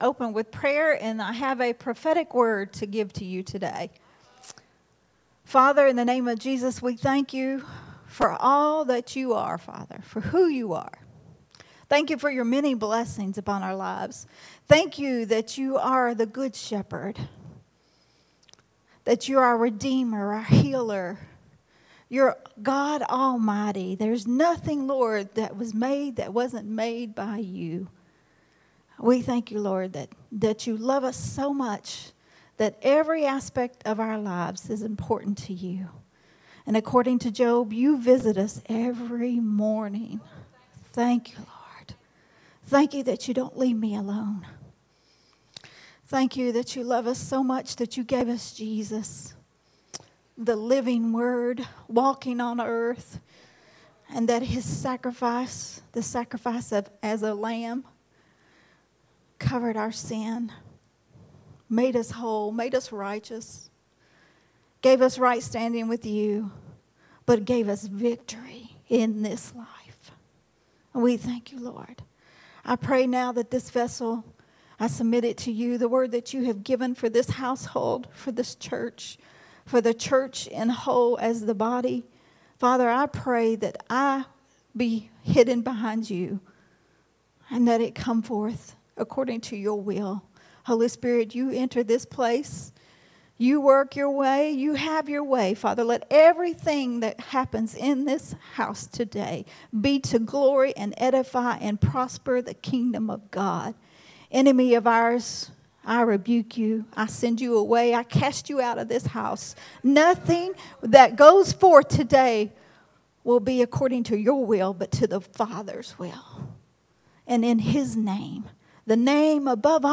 recorded at Unity Worship Center on June 2nd, 2024.